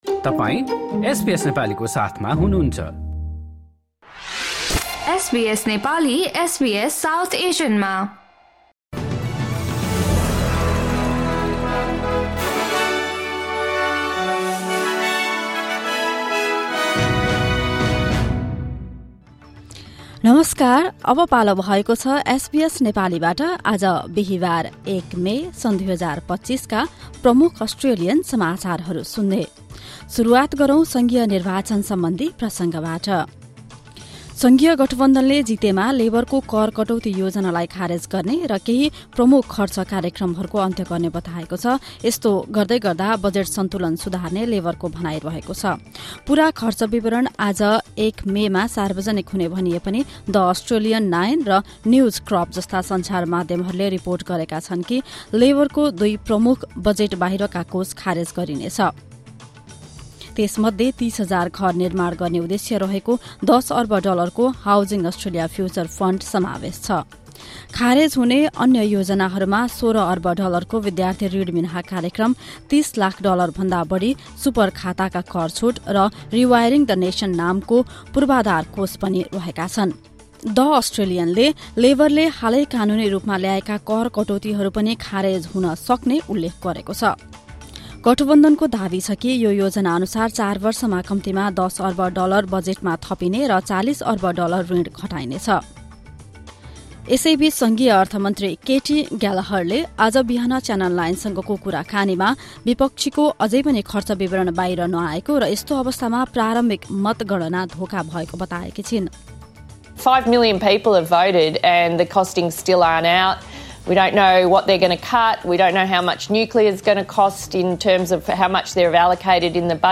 एसबीएस नेपाली प्रमुख अस्ट्रेलियन समाचार: बिहीवार, १ मे २०२५